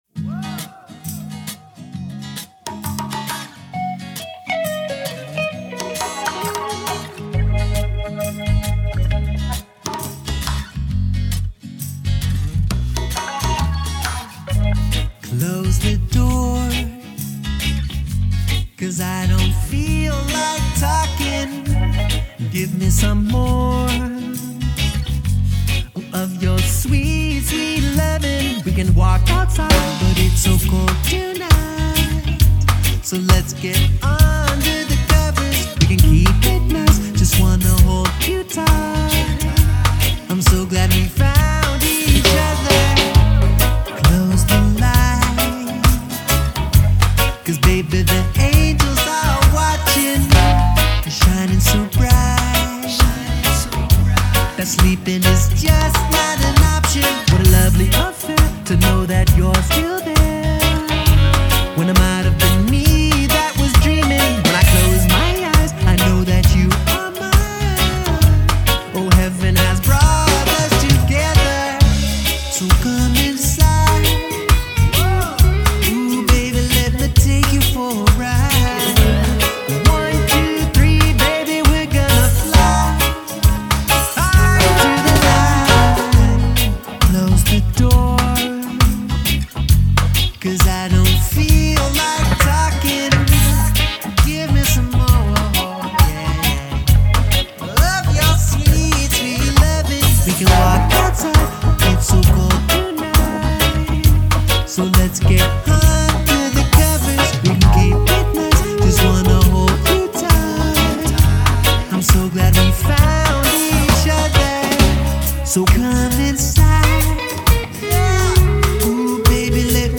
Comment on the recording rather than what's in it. Recorded at Livingston Studio, London, U.K.